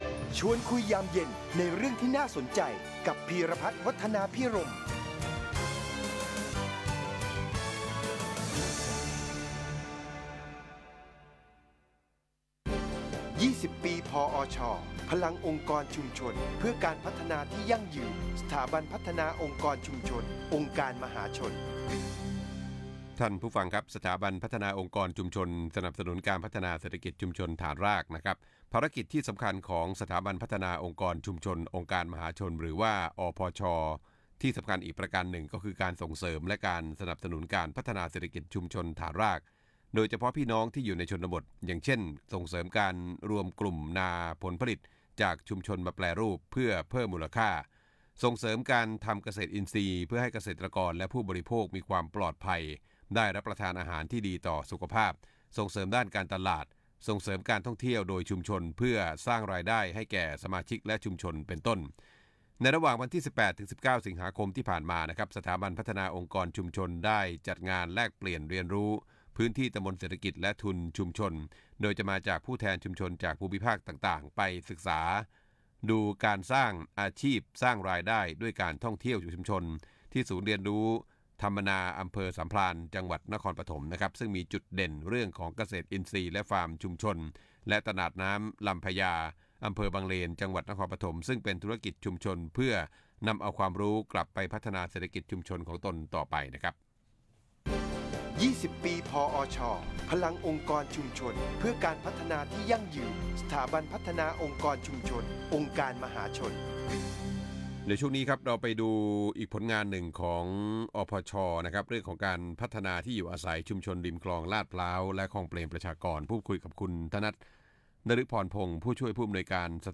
ฟังย้อนหลัง !! รายการ “ชวนคุยยามเย็น” ทางคลื่น FM 106 วิทยุครอบครัวข่าว ในประเด็น 20 ปี พอช. ตลอดเดือนสิงหาคม 2563 นี้